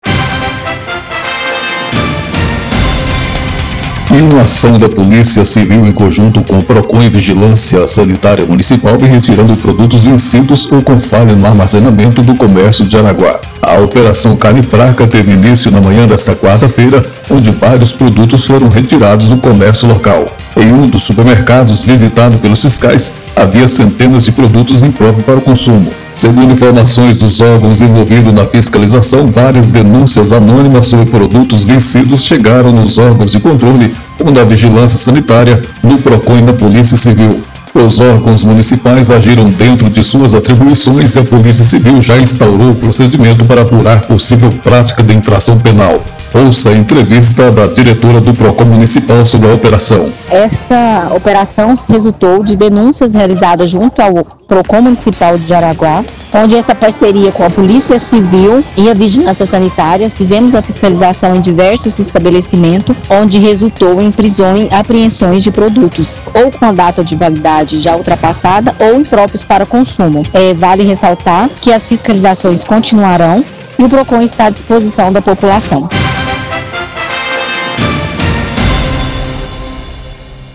Uma ação da Polícia Civil em conjunto com o Procon e Vigilância Sanitária Municipal vem retirando produtos vencidos ou com falha no armazenamento do comércio de Jaraguá. Ouça a entrevista com a diretora do Procon Municipal no áudio acima.